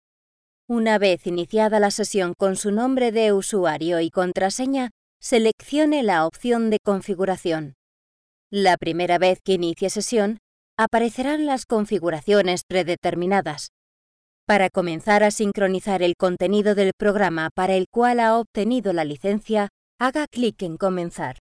Comercial, Joven, Natural, Versátil, Suave
E-learning